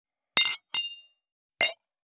333チャン,クリン,シャリン,チキン,コチン,カチコチ,チリチリ,シャキン,
コップ